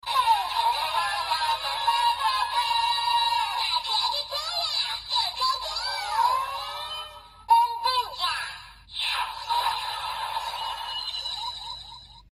奔奔者饱藏音效.MP3